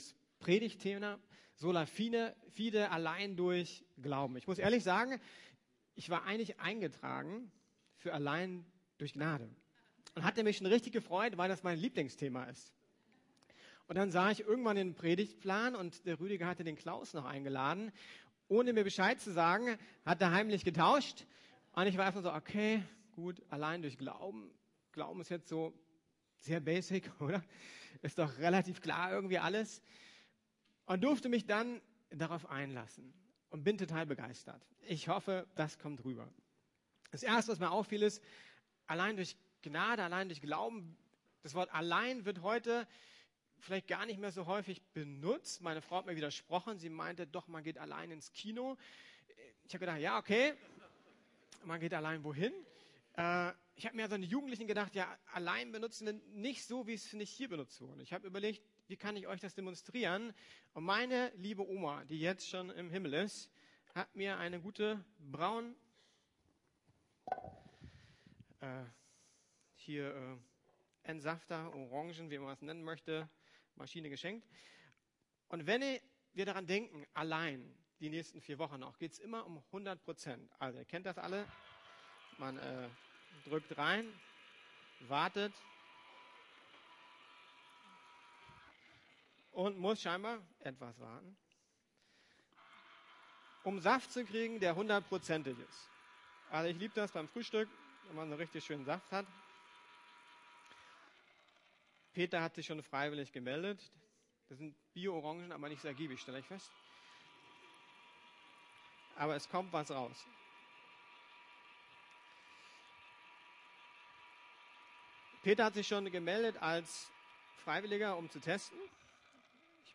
Allein der Glaube - Sola Fide ~ Predigten der LUKAS GEMEINDE Podcast